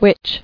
[witch]